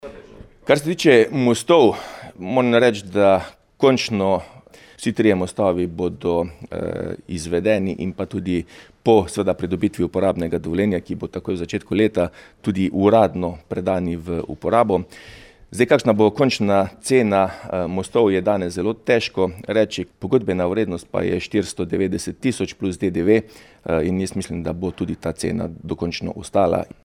Investicija naj bi bila v celoti zaključena ta mesec, poleg nameščenih mostov so zaključili tudi dohodne poti in asfaltiranje. Župan Mestne občine Slovenj Gradec Tilen Klugler :
IZJAVA TILEN KLUGLER 1_1.mp3